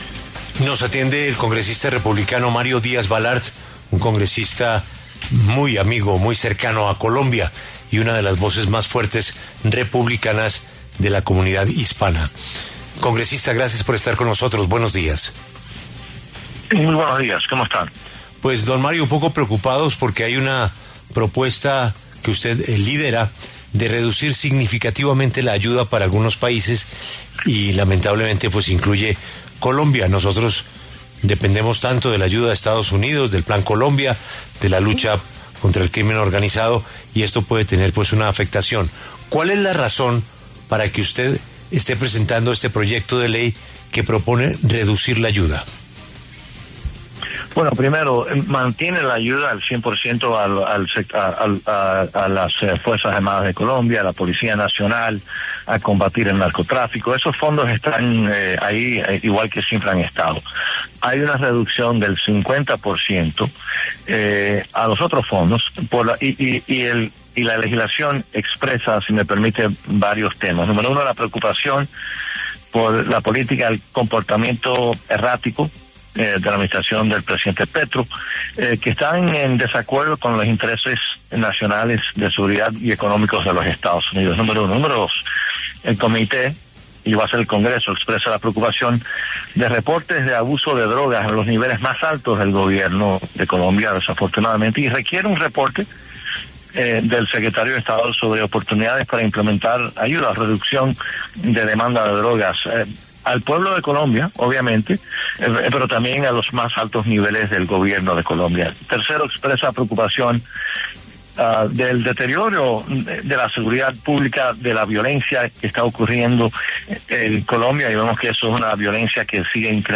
Por esta razón, el mismo Díaz-Balart pasó por los micrófonos de La W, con Julio Sánchez Cristo, para explicar el trasfondo del proyecto.